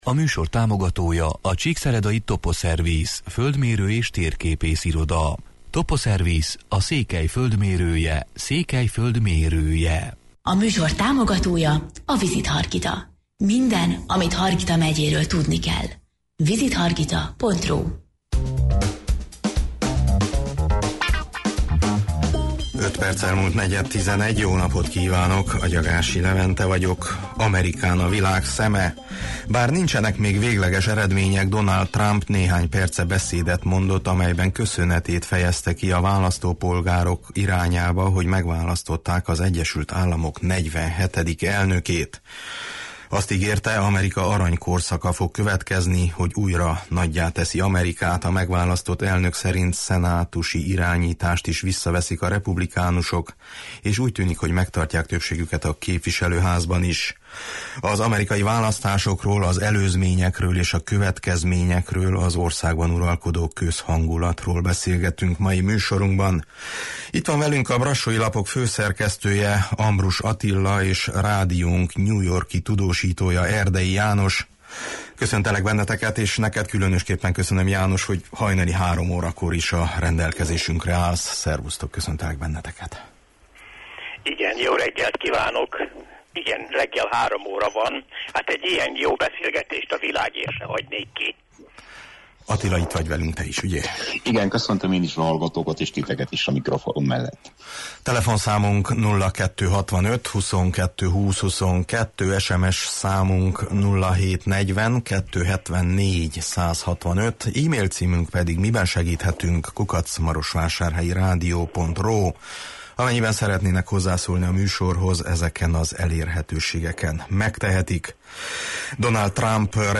Az amerikai választásokról, az előzményekről és a következményekről, az országban uralkodó közhangulatról beszélgetünk mai műsorunkban.